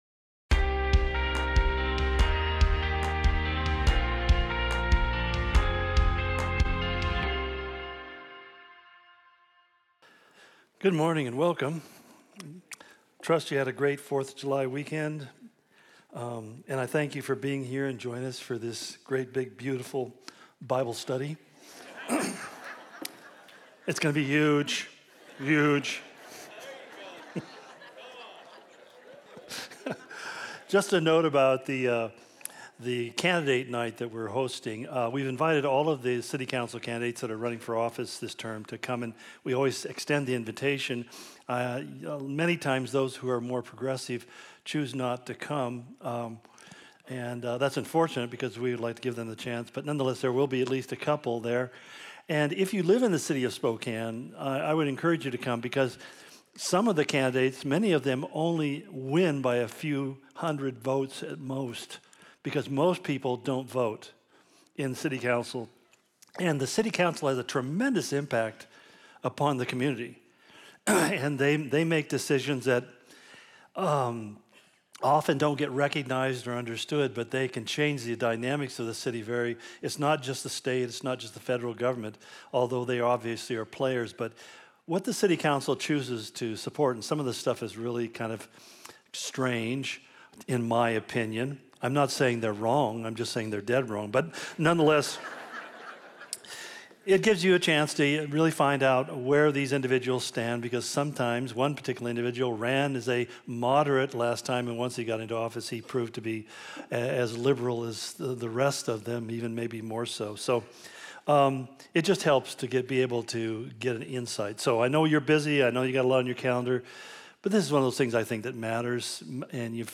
Calvary Spokane Sermon Of The Week podcast